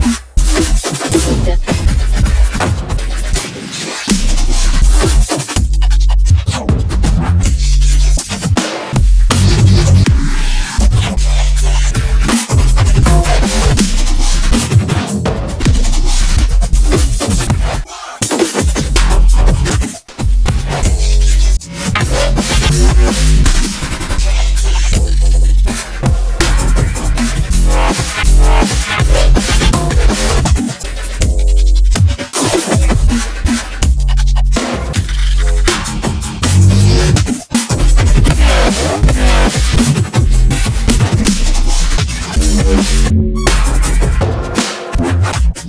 It takes a directory of audio files, chops it, shuffles it, and frankensteins it up into a single audio file according to your BPM, effects and other settings.
Random clips of Neurofunk tracks.